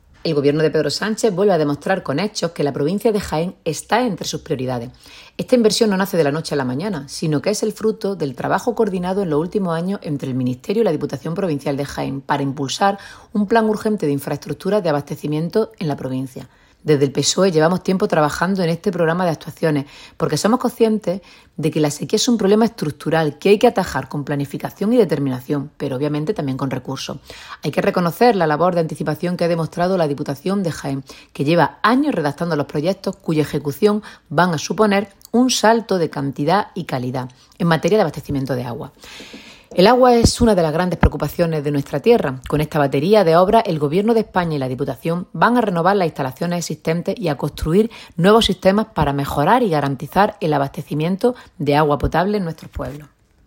Cortes de sonido
Ana-Cobo-obras-hidraulicas.mp3